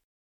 Weapon_Break.ogg